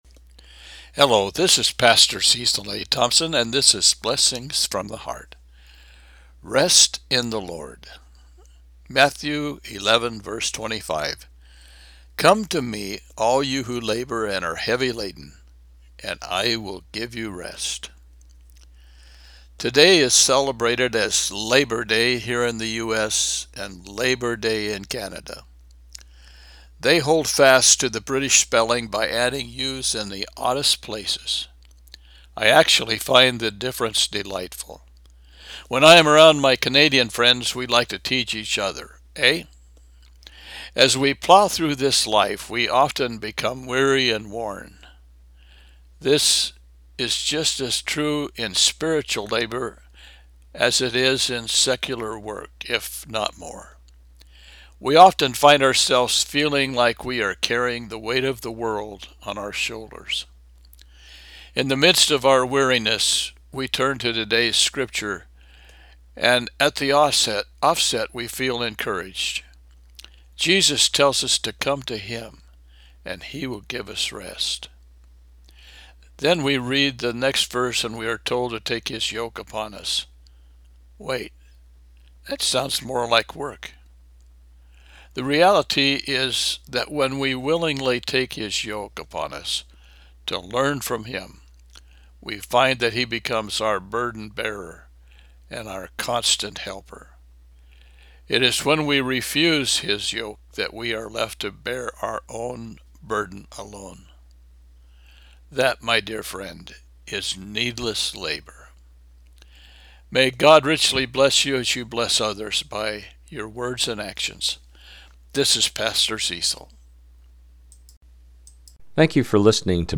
Matthew 11:25 – Devotional